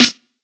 kits/OZ/Snares/SN_Billboard.wav at main
SN_Billboard.wav